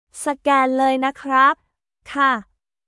サゲーン ルーイ ナ クラップ/カー